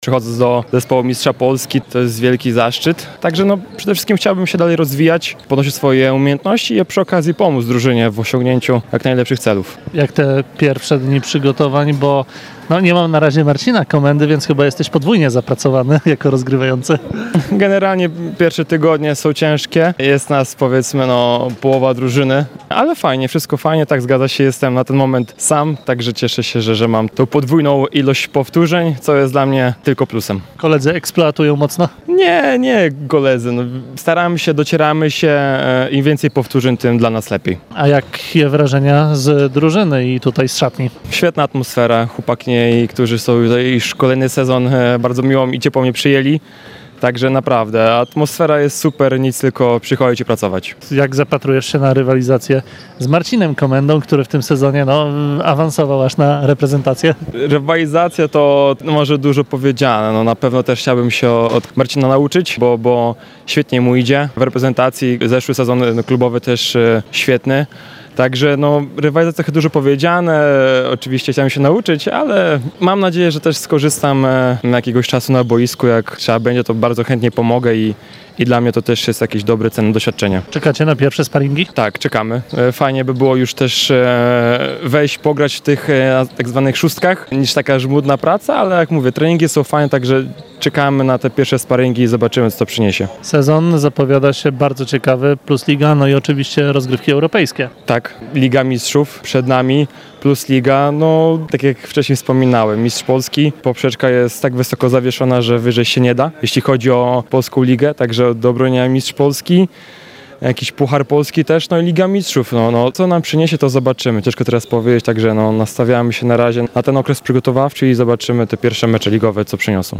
Cała rozmowa w materiale dźwiękowym